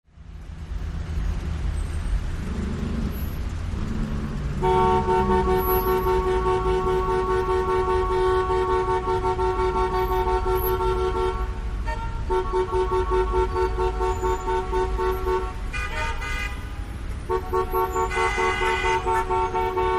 City Traffic
City Traffic is a free ambient sound effect available for download in MP3 format.
283_city_traffic.mp3